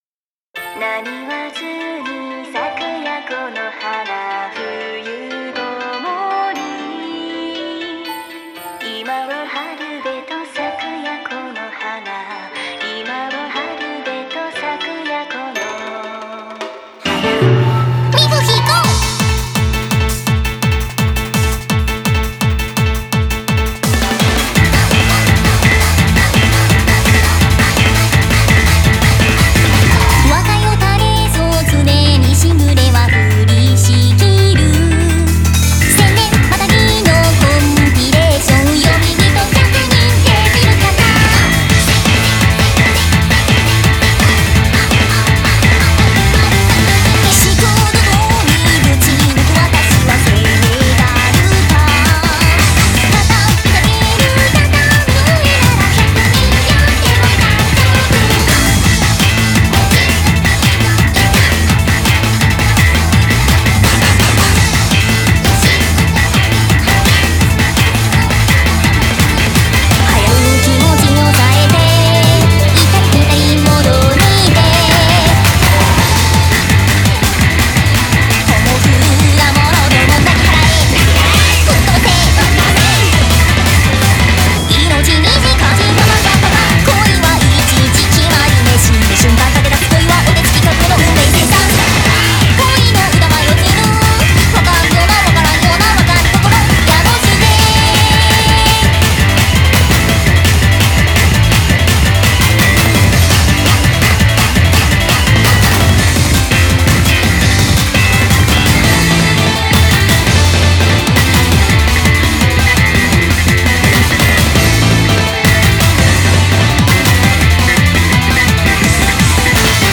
BPM120-168
Audio QualityPerfect (High Quality)
a pretty catchy one